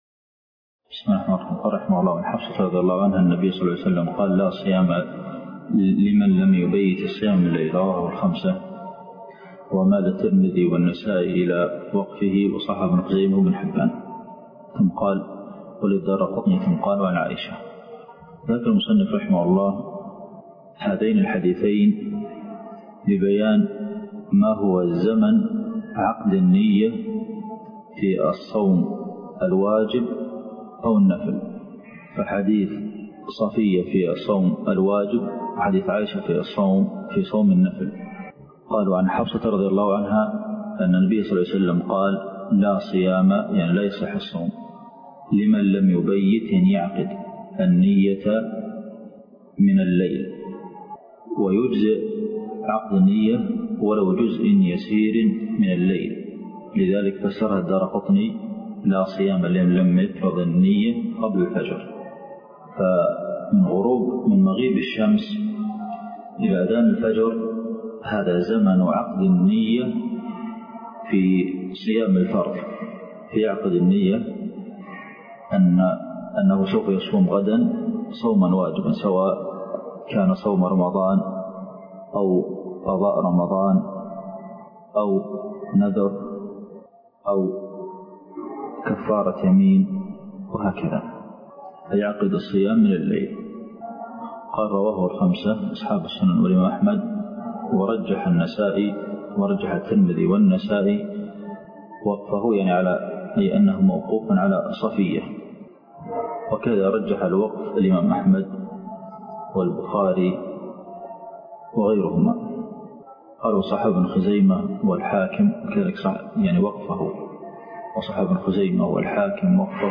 الدرس (3) شرح بلوغ المرام دورة في فقه الصيام - الشيخ عبد المحسن القاسم